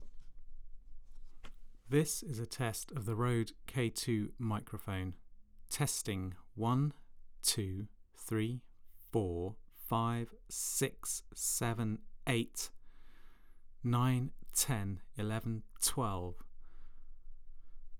All clips were recorded via the preamps on my UA Apollo interface, I’ve endeavoured to maintain the same conditions and distances between mic and source for each comparison, but there may be small variations.
Firstly, male voice (don’t worry, I’m not singing)
rode-k2-stock-m-vox.wav